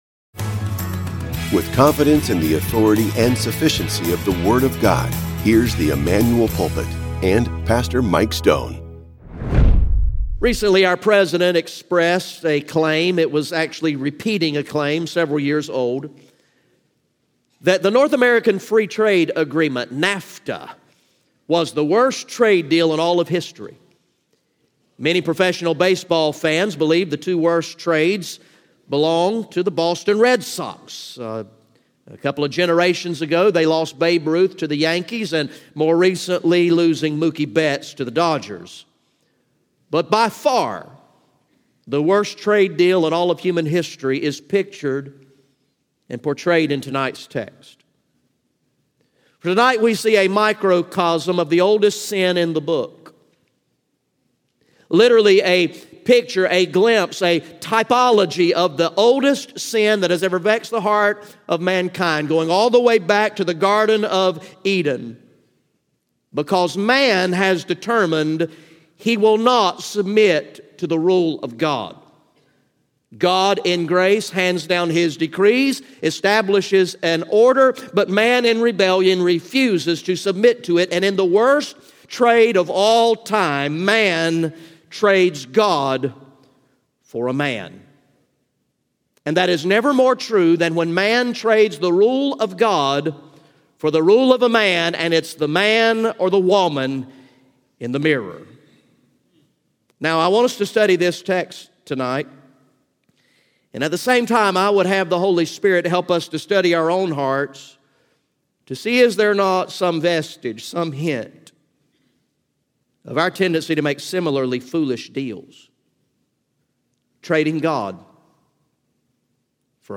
GA Message #14 from the sermon series titled “Long Live the King!